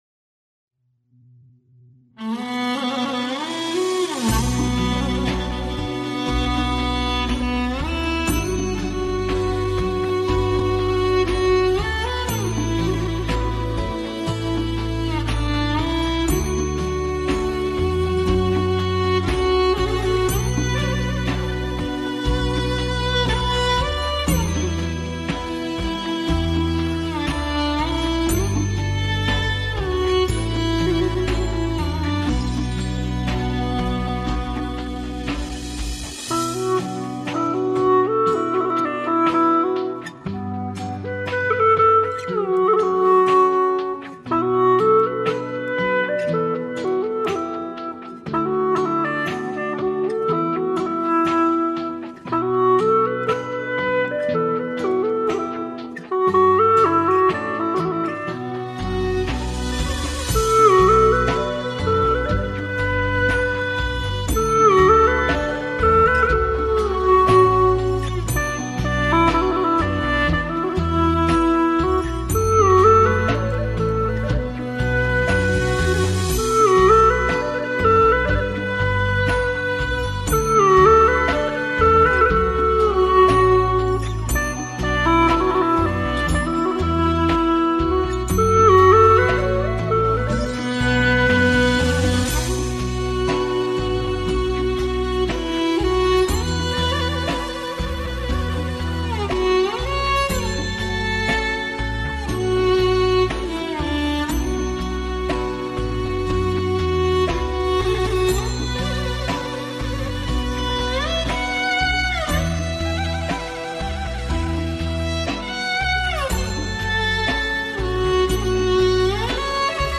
改编藏族民谣
调式 : G